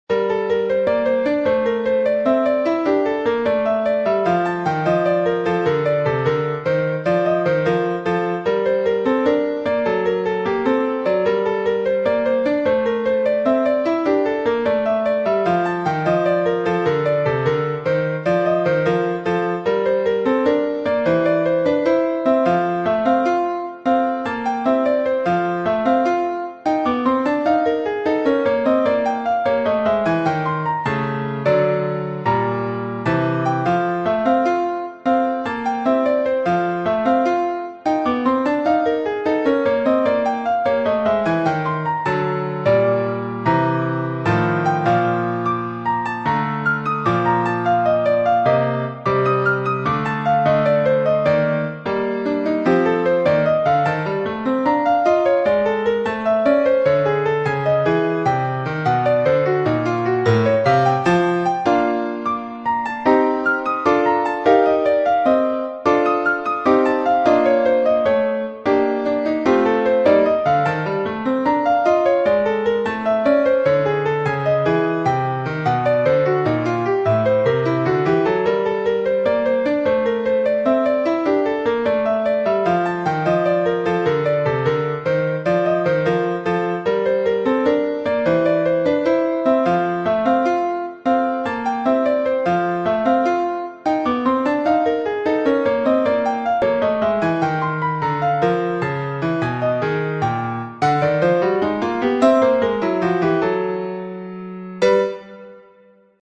So this is my first go at a folk type tune.
The piece is intended to be played on folk instruments, such as bouzouki and bass guitar.
I can play accordion and flute so I might get a performance that way Attached are (1) The first 2 lines of the score (2) The audio generated by the score. The structure is A . B . C . A . Coda. 78 Tonebase complete audio Like